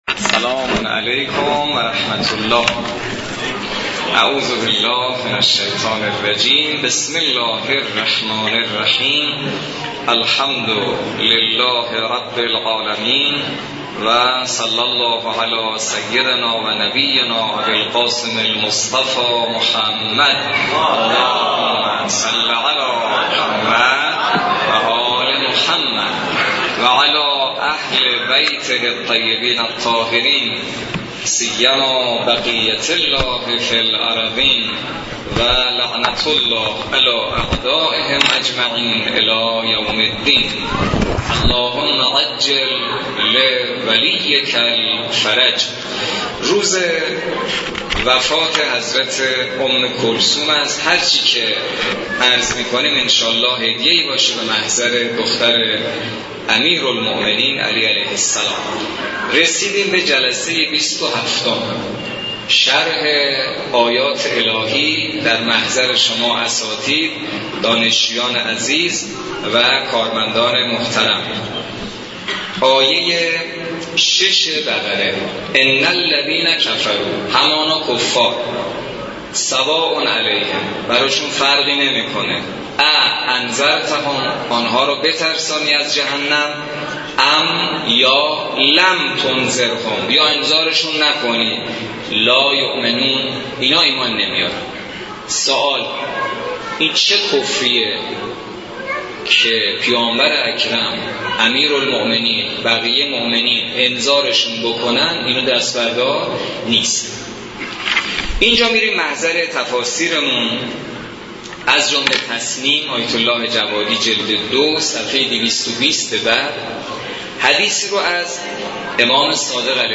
نهمین جلسه تفسیر سوره مبارکه بقره توسط حجت‌الاسلام والمسلمین حسینی نماینده محترم ولی فقیه و امام جمعه کاشان در مسجد دانشگاه کاشان برگزار گردید.